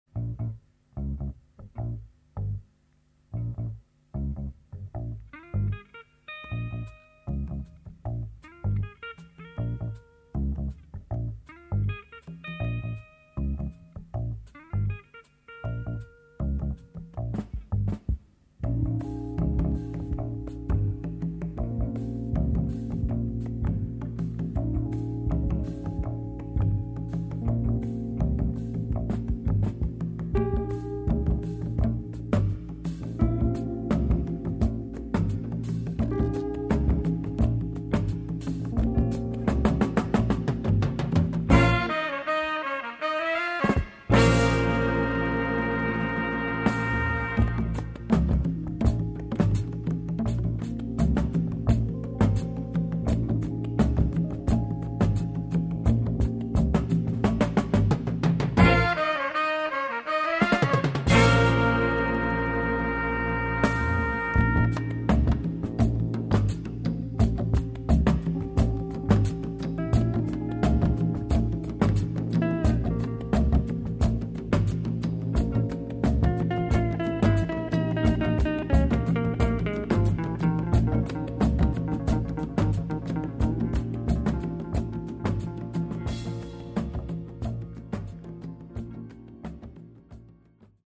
Afro、Funk、Jazz、Calypso、Mentなど様々な音楽を消化したオリジナリティ溢れる傑作。